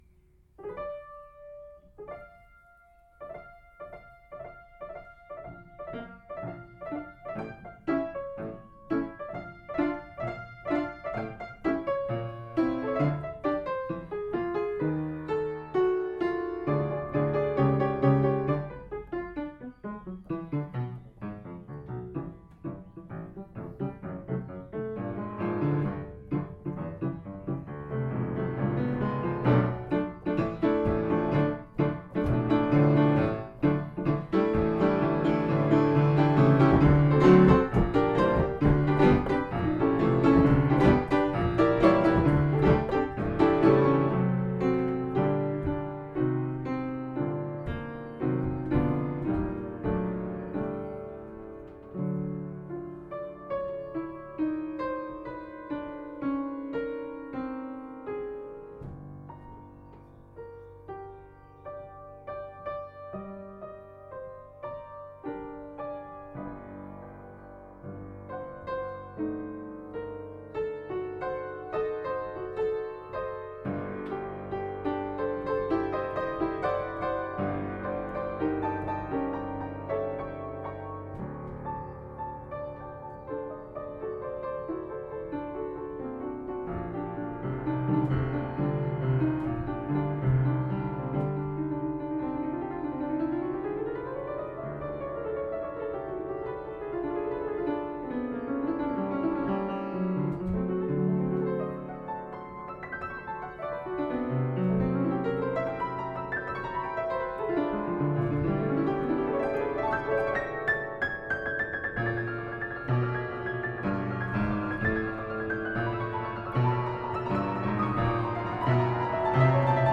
piano improvisation 20.04.2020